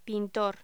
Locución: Pintor
voz